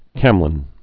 (kămlən)